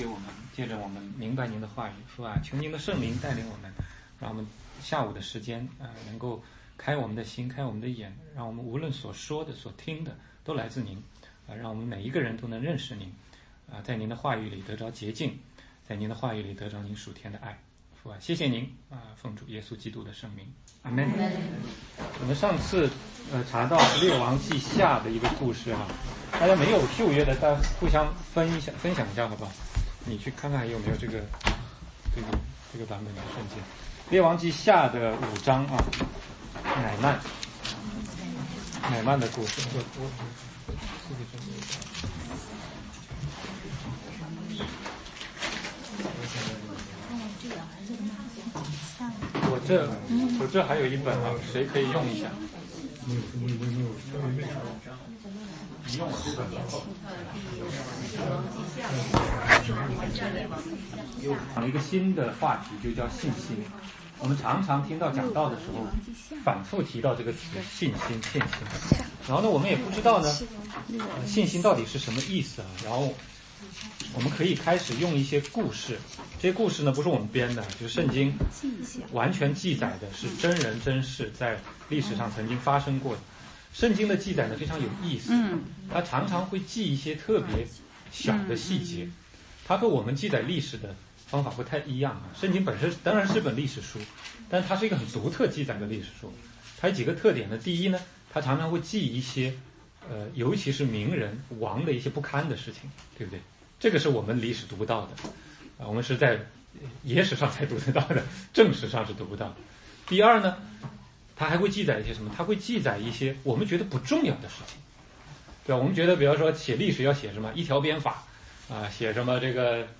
16街讲道录音 - 信心